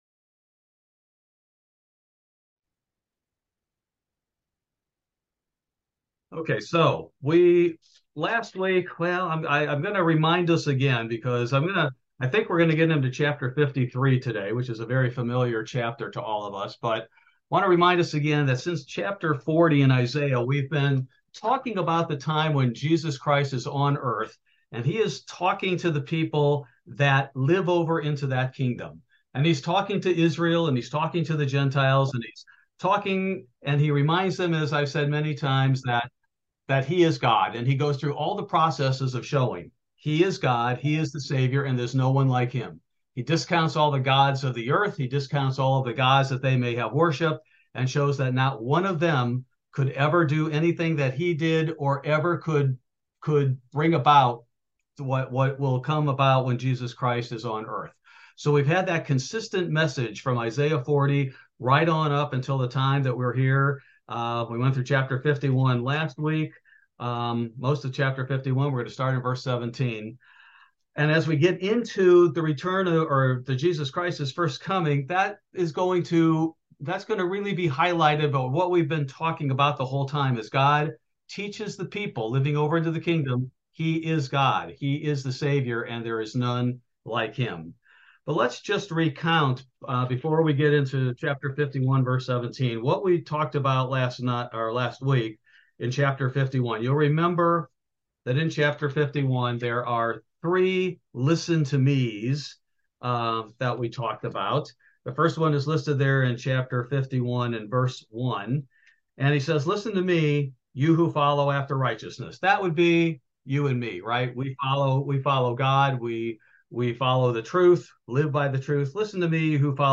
Bible Study: September 13, 2023